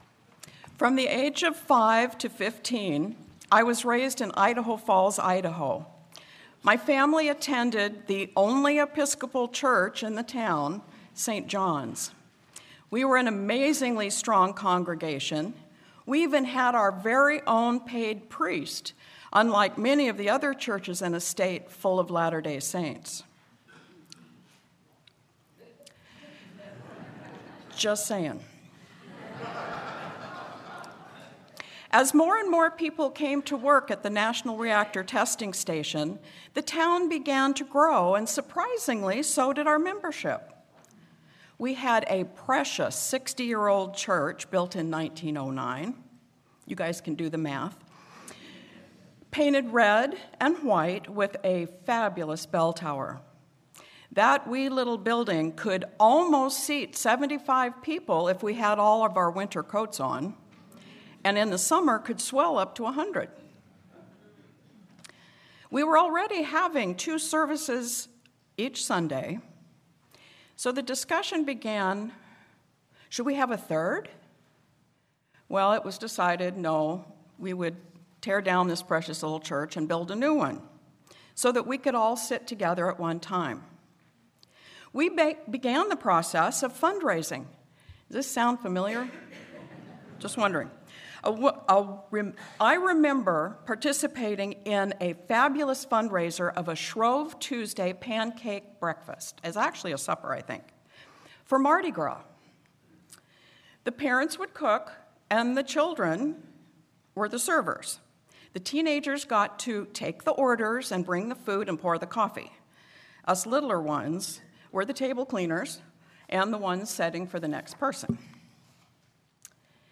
Audio timing notes: 0:00 Reflection 4:58 Sermon